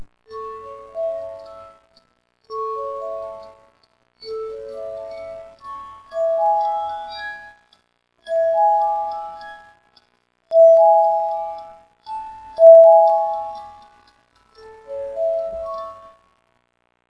ここにまとめてオルゴールを録音してWAVEファイルにしてみました。
いまいちの音質です。
クマがハンドルをまわします。
オルゴール]